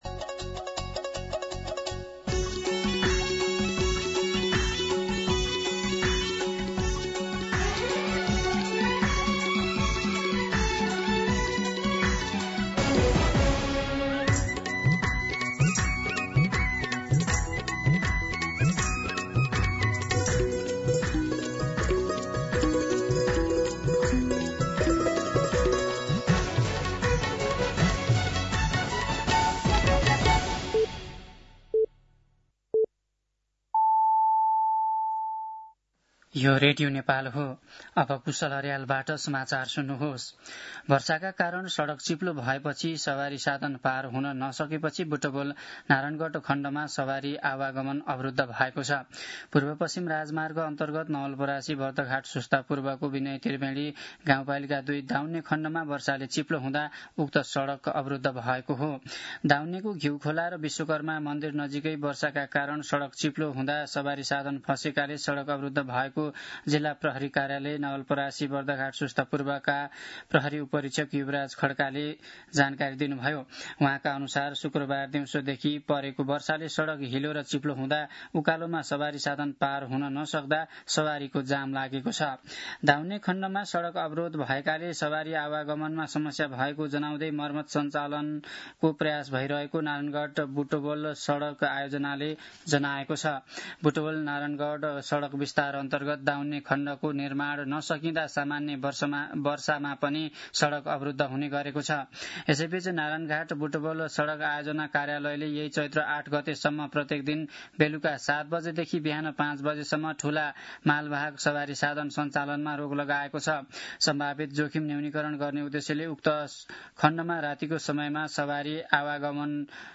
दिउँसो १ बजेको नेपाली समाचार : ७ चैत , २०८२